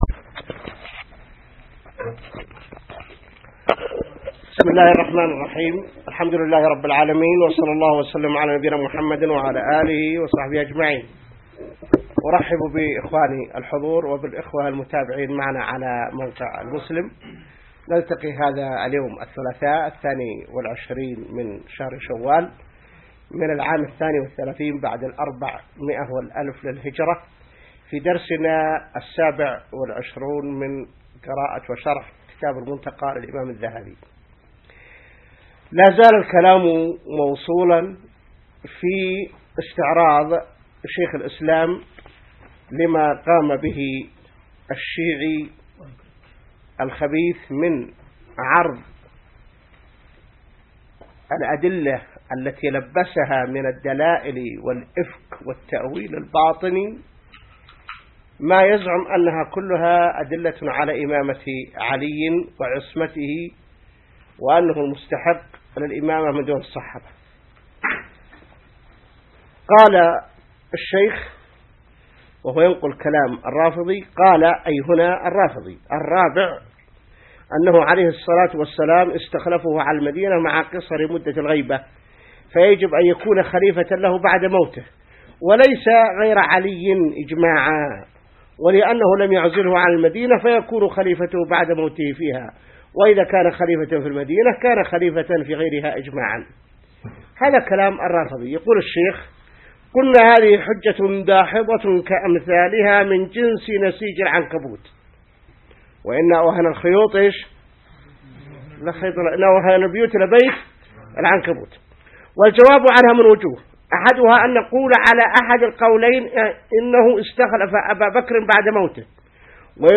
الدرس 27 من شرح كتاب المنتقى | موقع المسلم